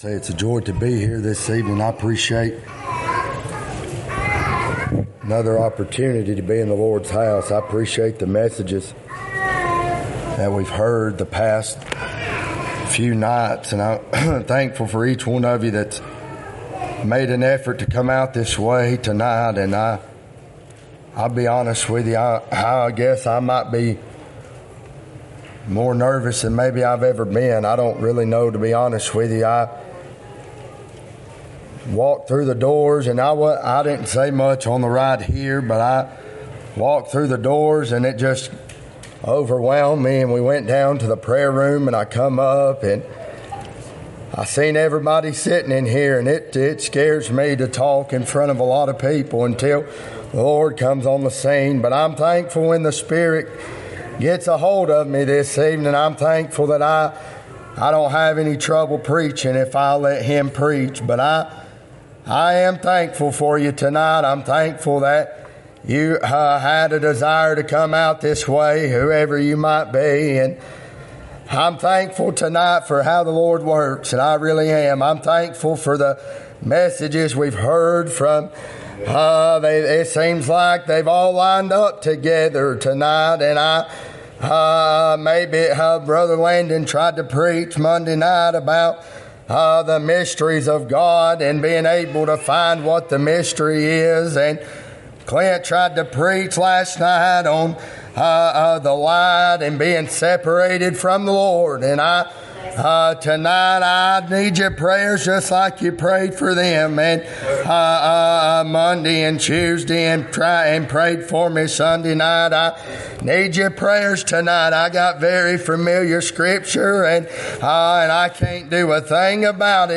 Hebrews 10:1 Service Type: Sunday 2025 What spirit are you following?